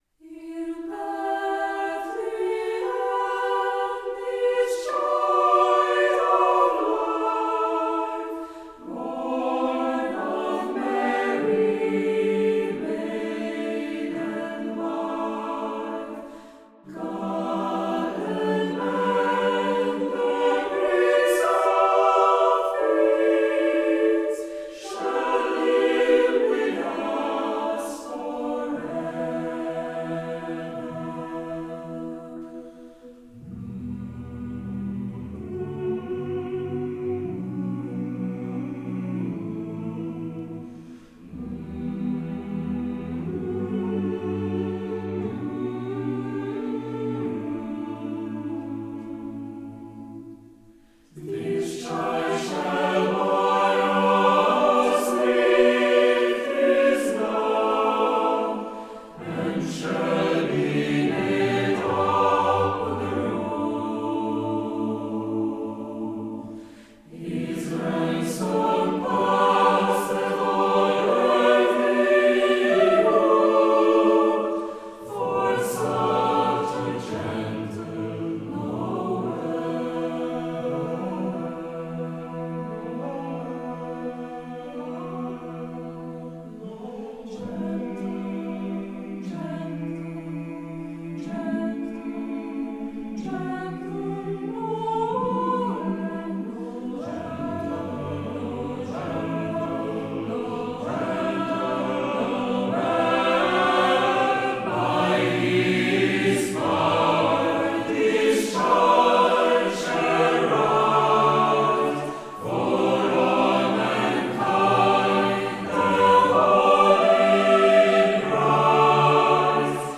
Instrumente - Mixed Choir Tempo - Medium BPM - 89